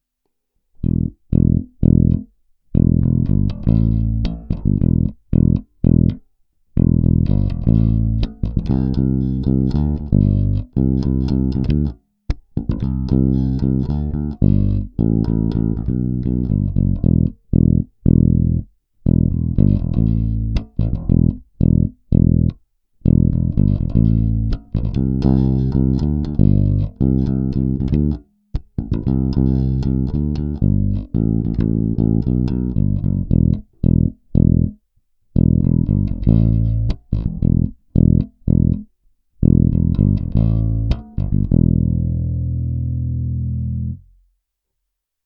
Nejstarší kousek má i nejdřevitější zvuk.
Následující nahrávky byly provedeny rovnou do zvukovky. Použité struny byly nějaké niklové padesátky ve slušném stavu.
Bonusové nahrávky - basy a výšky přidány přibližně o polovinu rozsahu